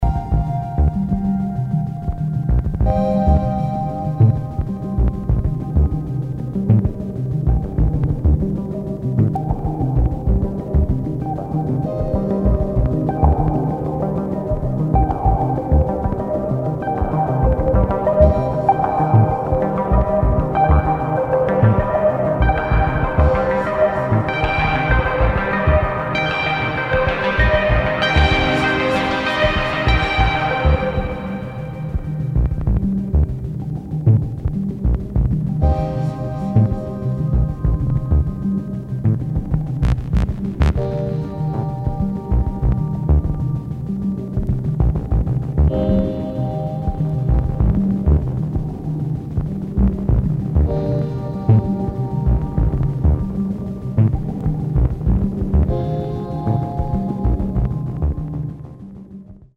[ BASS / DOWNBAET ]